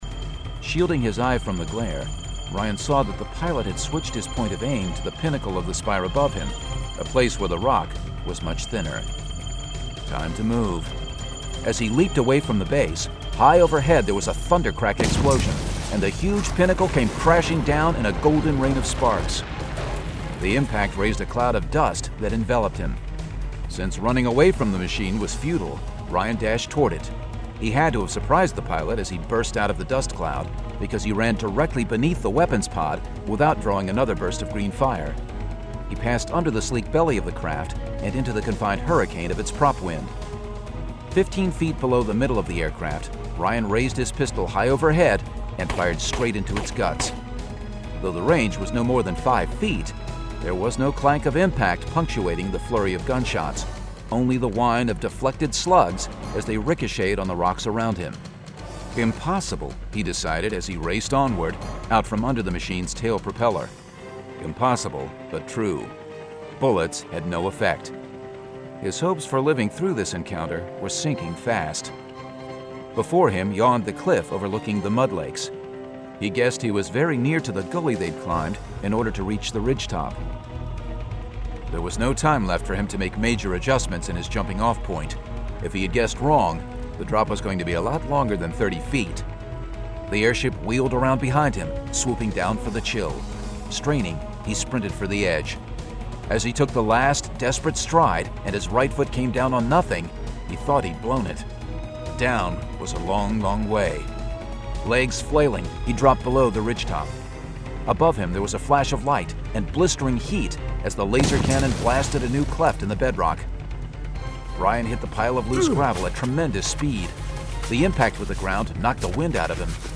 Full Cast. Cinematic Music. Sound Effects.
[Dramatized Adaptation]